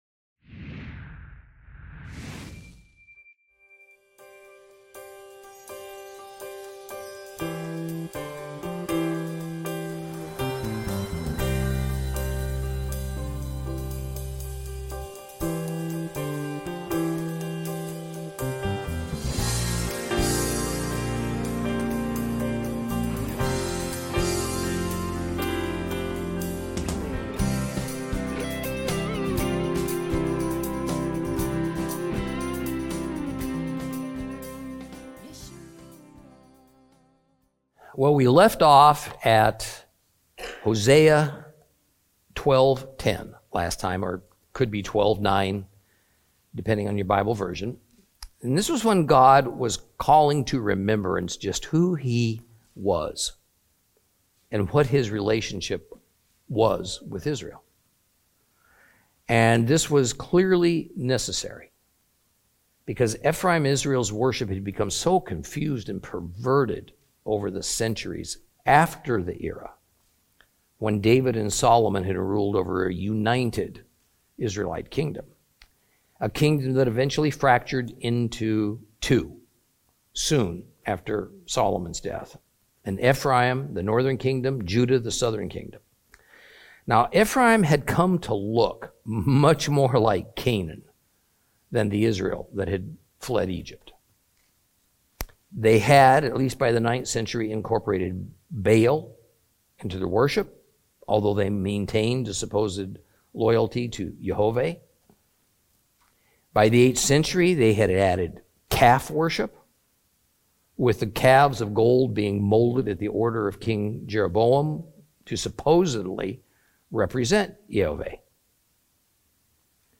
Teaching from the book of Hosea, Lesson 22 Chapters 12 and 13.